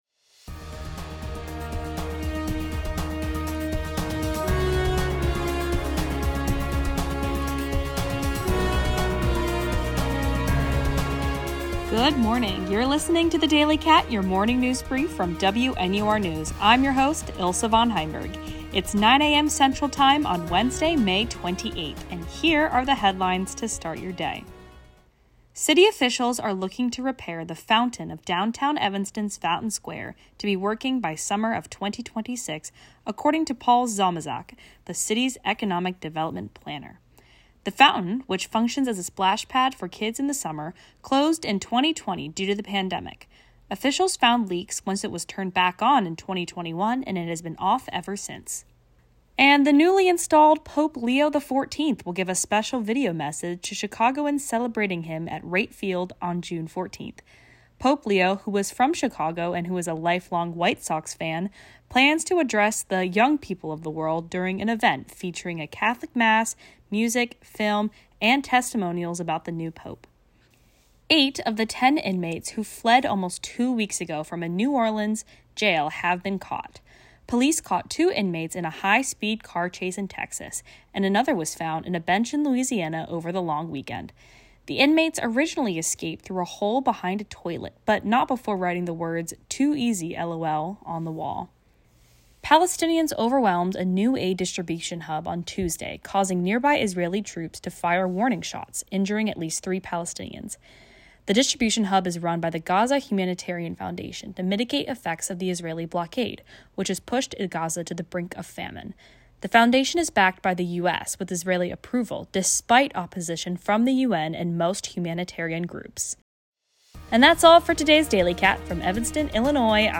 DATE: May 28, 2025 Evanston Fountain Square, Gaza humanitarian aid, Pope Leo Chicago, White Sox, New Orleans escaped inmates. WNUR News broadcasts live at 6 pm CST on Mondays, Wednesdays, and Fridays on WNUR 89.3 FM.